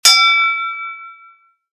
ding.wav